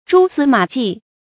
蛛絲馬跡 注音： ㄓㄨ ㄙㄧ ㄇㄚˇ ㄐㄧˋ 讀音讀法： 意思解釋： 蜘蛛絲；馬蹄痕。